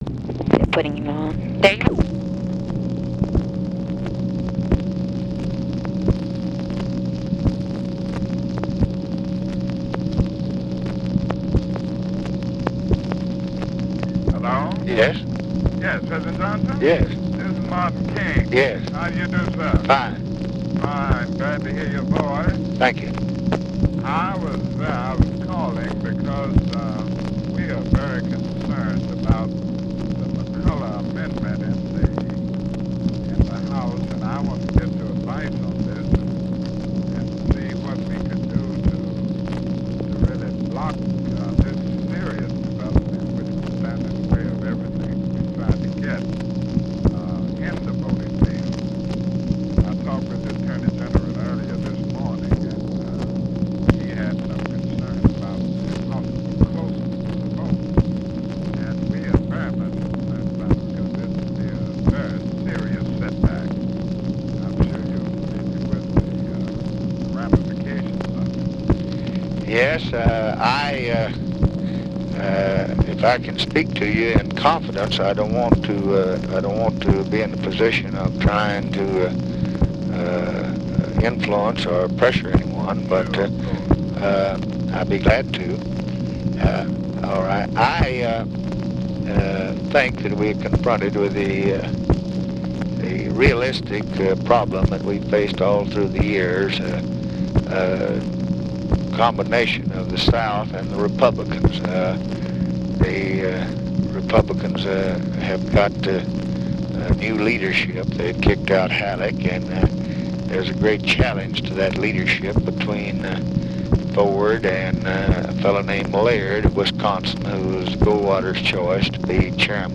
Conversation with MARTIN LUTHER KING, July 8, 1965
Secret White House Tapes